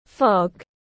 Sương mù tiếng anh gọi là fog, phiên âm tiếng anh đọc là /fɒɡ/.
Fog /fɒɡ/
fog.mp3